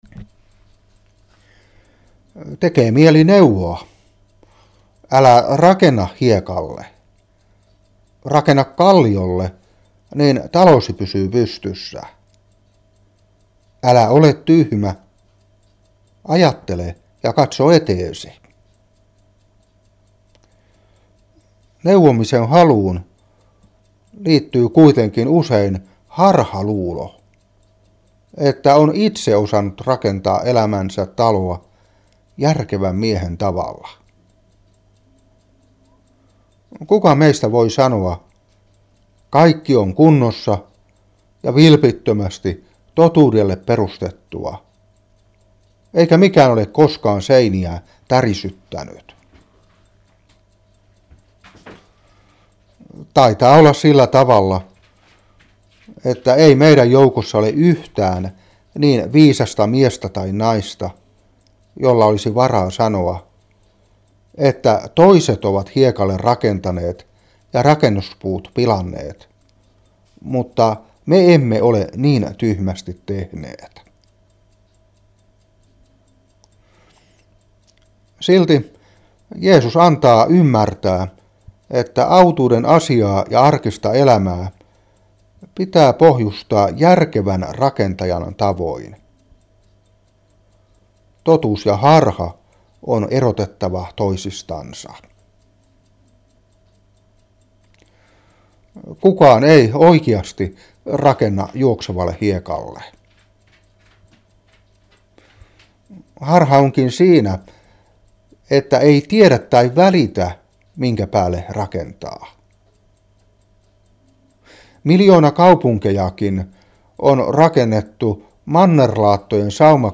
Saarna 2012-7.